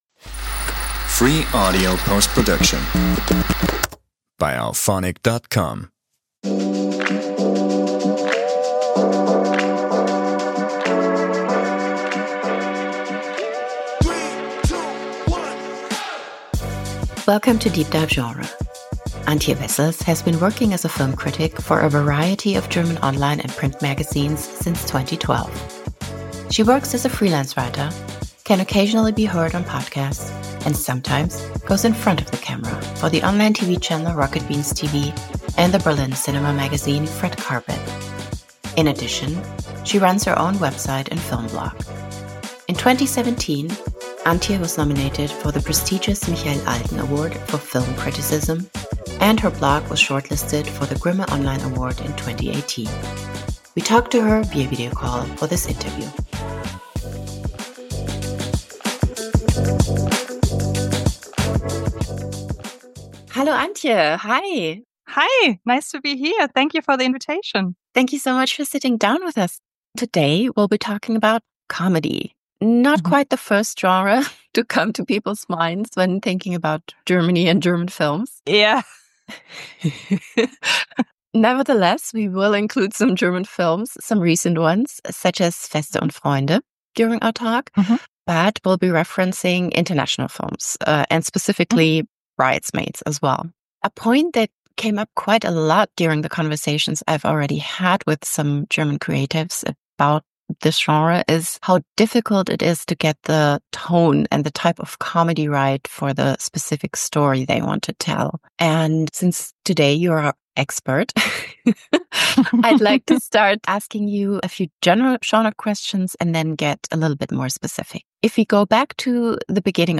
Enjoy our wonderful conversation with Caroline!